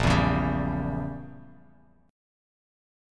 A#dim7 chord